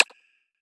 sliderbar-notch.wav